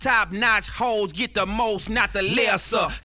Vox